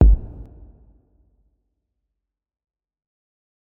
SouthSide Kick Edited (31).wav